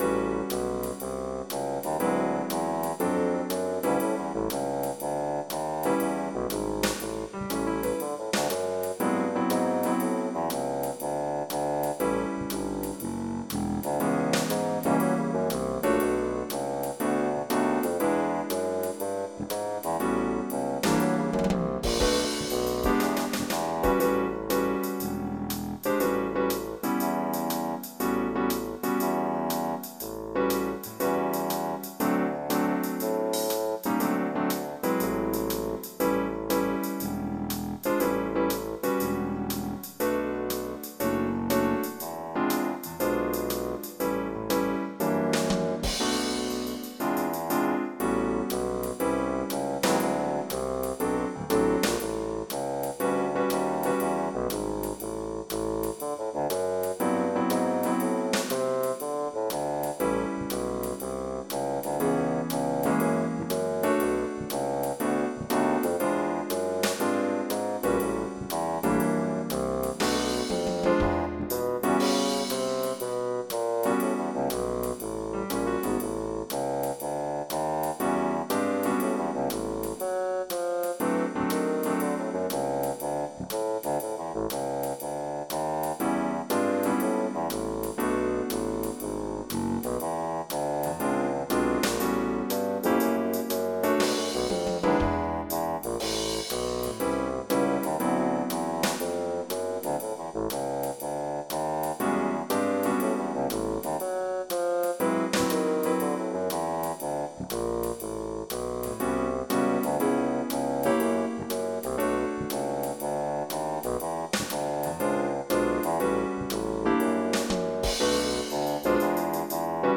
MIDI Music File
blues-f.mp3